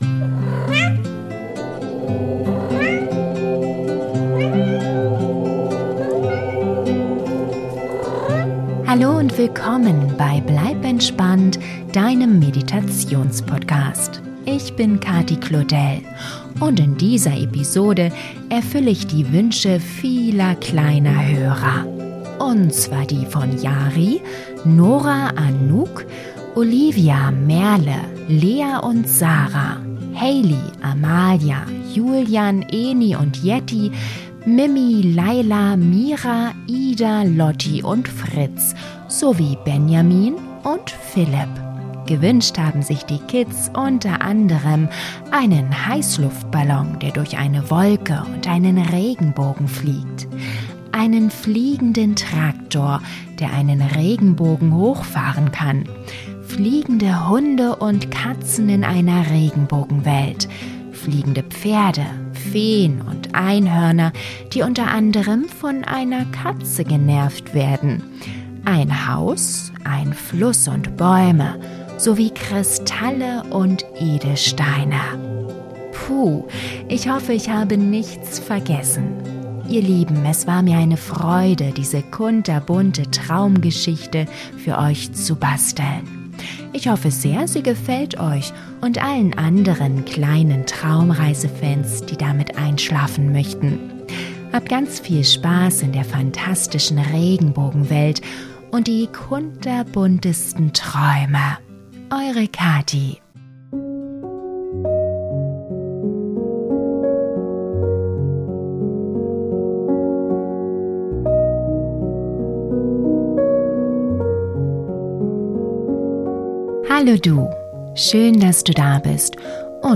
Traumreise für Kinder zum Einschlafen - Eine fantastische Regenbogenwelt - Regenbogen Einhorn Geschichte ~ Bleib entspannt!